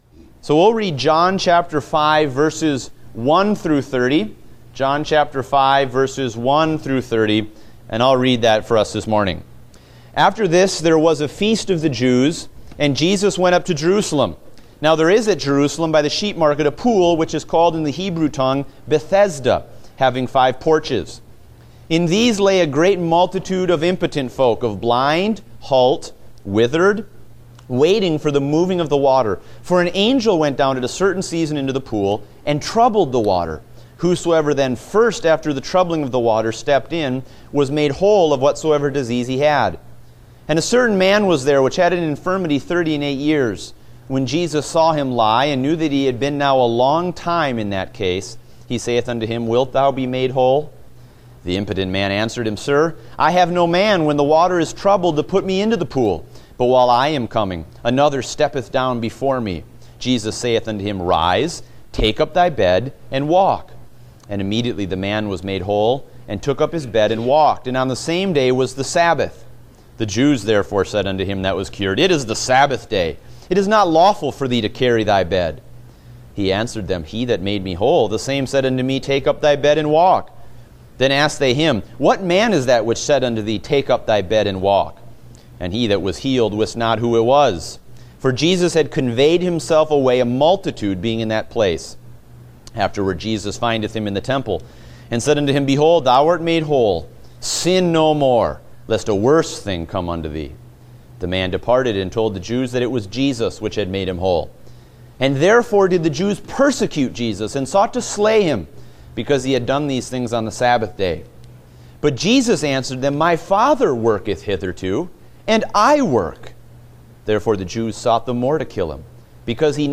Date: July 24, 2016 (Adult Sunday School)